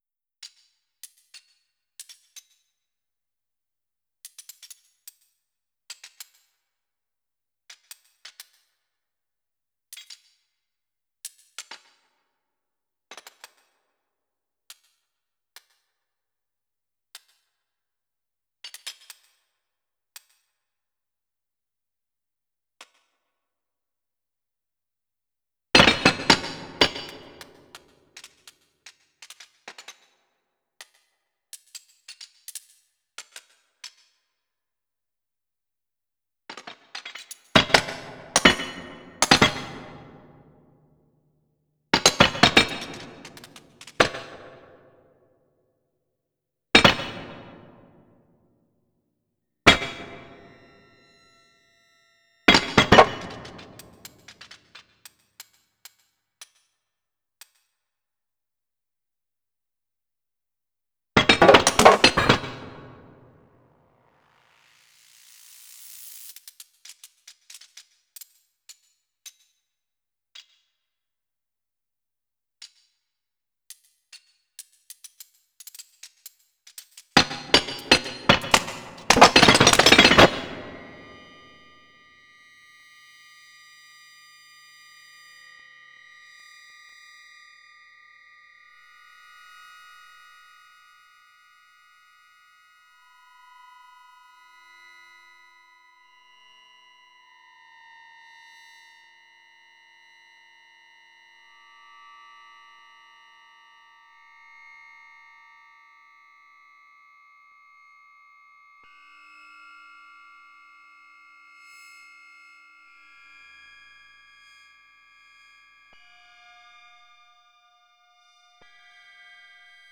electroacústica    electroacoustic
La obra fue realizada utilizando exclusivamente un sámpler y una computadora para edición de las muestras digitales, y la secuenciación MIDI de la partitura.
A pesar de estar totalmente secuenciada, ciertos pasajes presentan sutiles diferencias tímbricas cada vez que la pieza es tocada, ya que algunos parámetros están modulados por un generador de números aleatorios. Todo el material sonoro está derivado de dos grabaciones realizadas por mí con un grabador portátil de casete en las calles de Montevideo, y luego digitalizadas. Ambos materiales, que aparecen con distintos tipos y grados de procesamiento a lo largo de la pieza, son muy característicos del paisaje sonoro de esta ciudad.